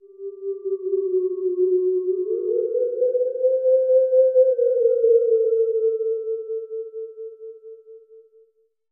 ho_ghost_theremin_01_hpx
Ghostly howling sounds resonate with reverb.